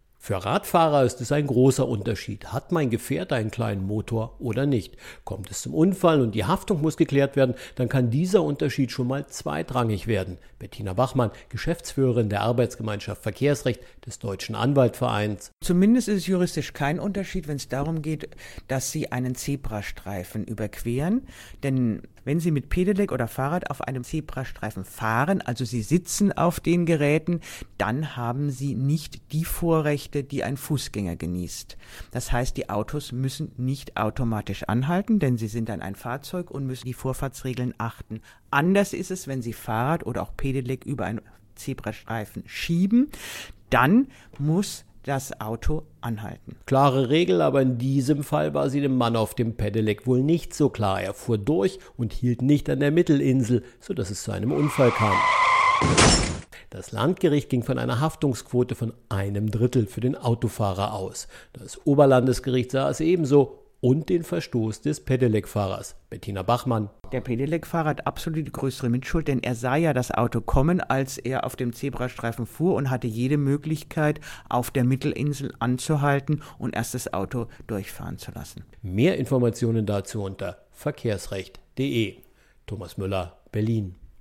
Auto, DAV, O-Töne / Radiobeiträge, Ratgeber, Recht, , , , ,
Magazin: Raststätten-Toiletten müssen nicht kostenlos sein